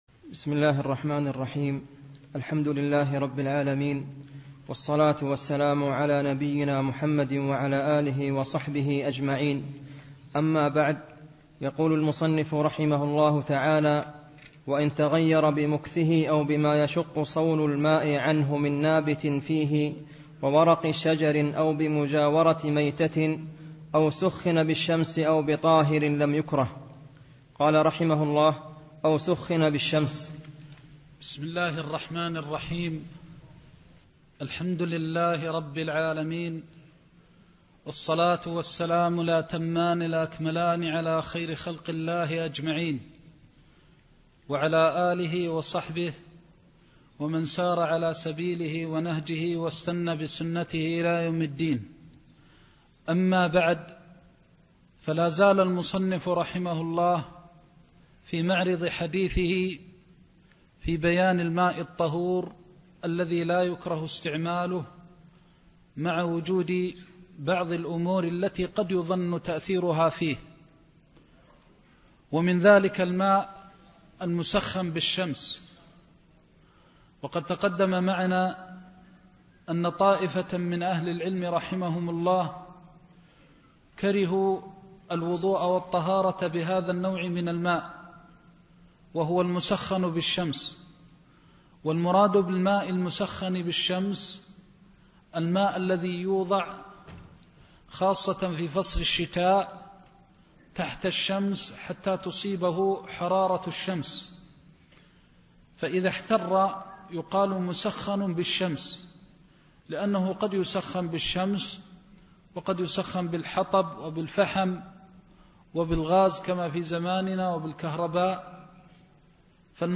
زاد المستقنع كتاب الطهارة (5) درس مكة